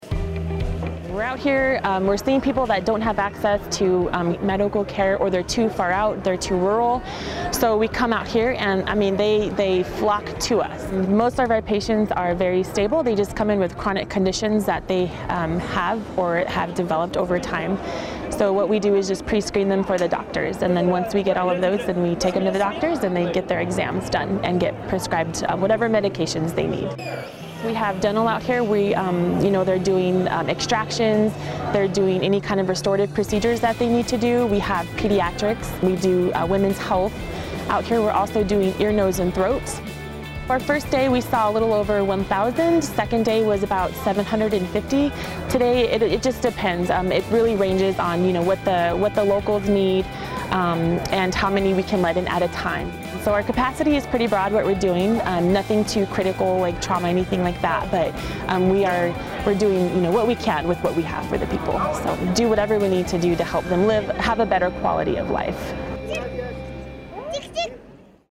African Lion 17: HCA Radio